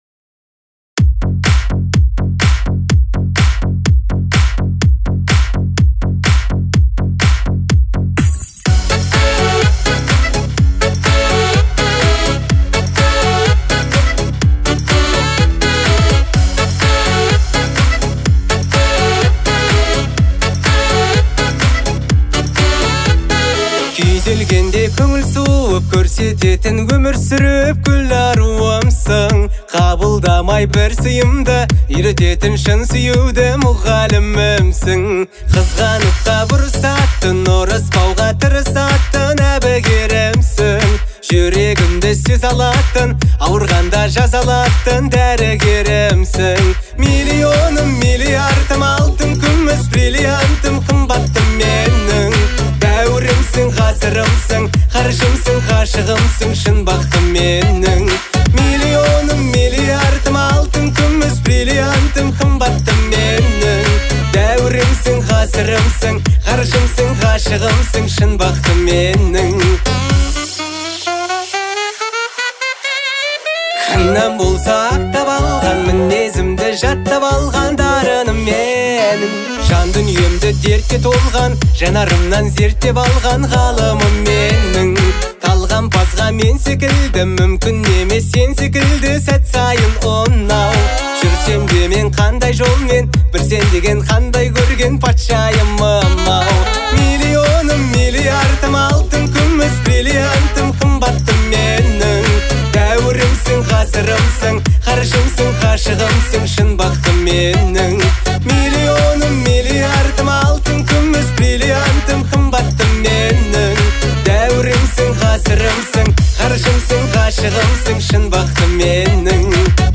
Звучание трека отличается ритмичностью и мелодичностью